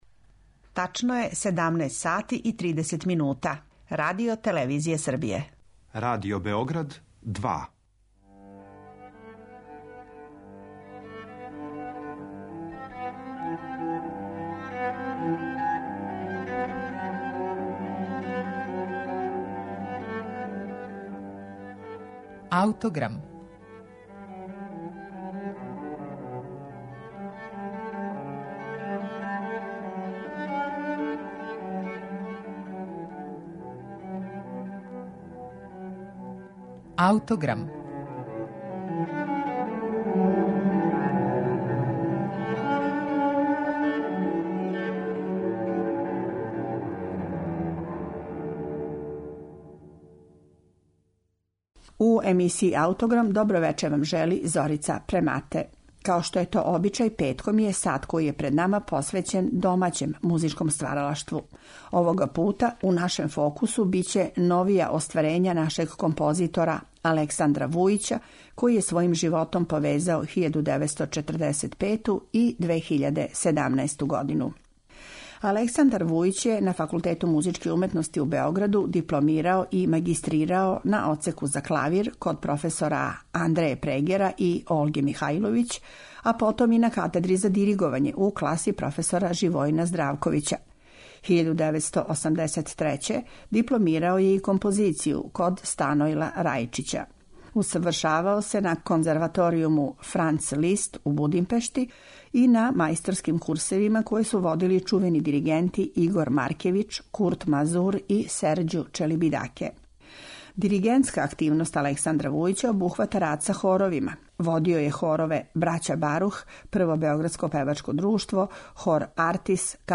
кончертино за виолину и гудаче којим је